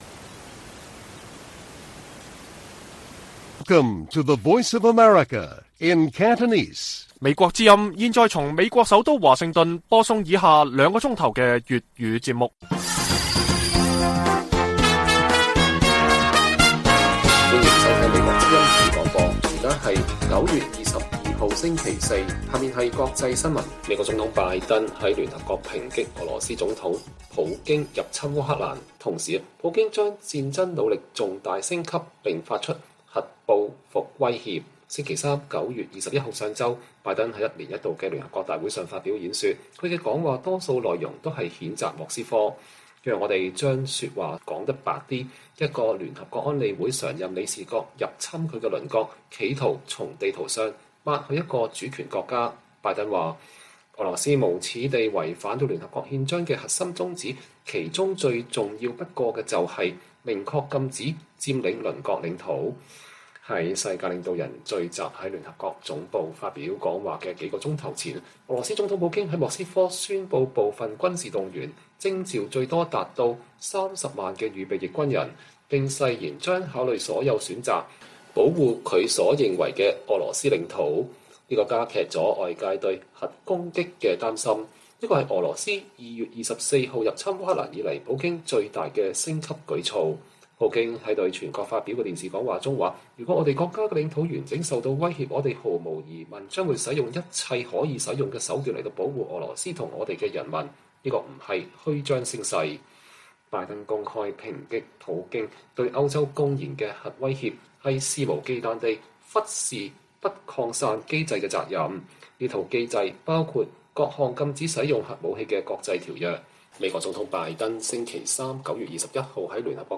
粵語新聞 晚上9-10點: 拜登聯大發言譴責俄羅斯“無恥”侵烏